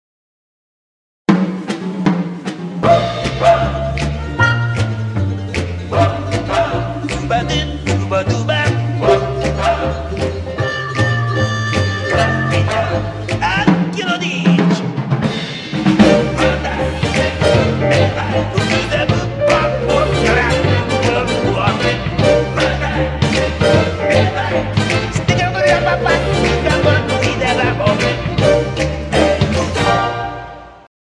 Italian